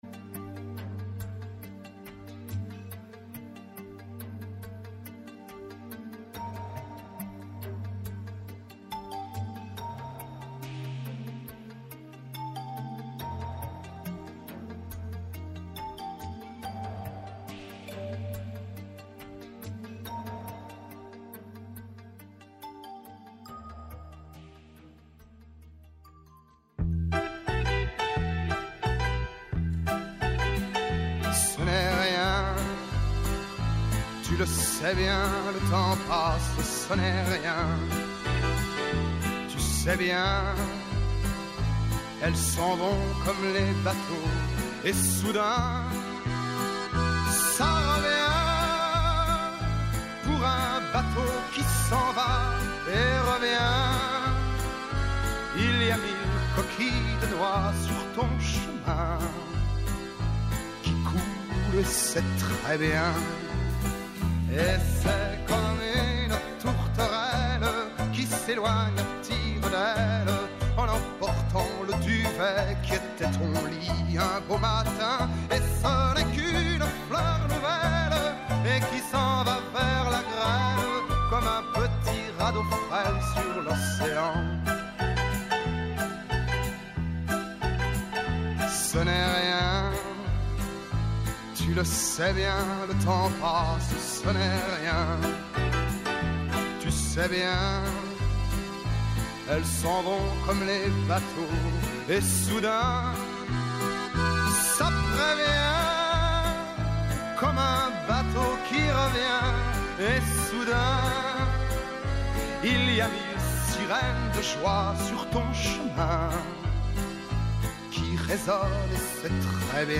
Συζήτηση με τους πρωταγωνιστές των γεγονότων.